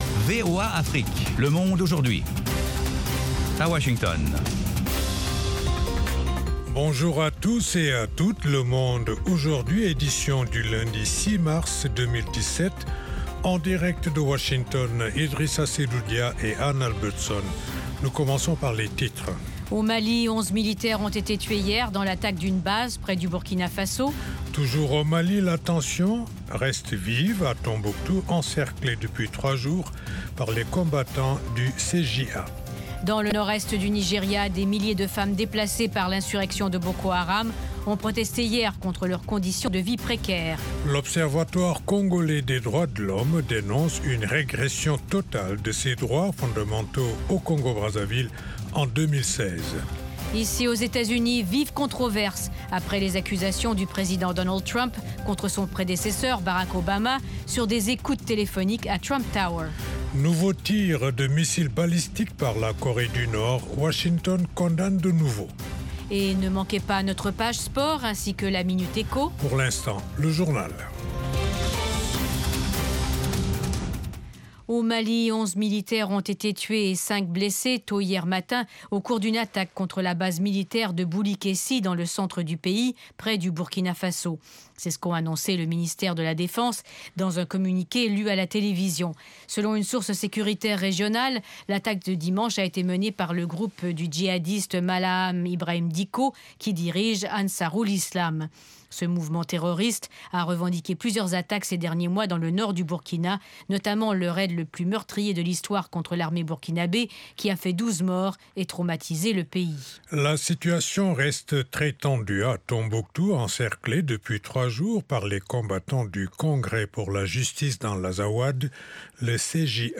Interviews, reportages de nos envoyés spéciaux et de nos correspondants, dossiers, débats avec les principaux acteurs de la vie politique et de la société civile. Le Monde Aujourd'hui vous offre du lundi au vendredi une synthèse des principaux développementsdans la région.